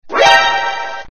suspense | Games EduUu
suspense-games-eduuu.mp3